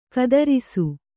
♪ kadarisu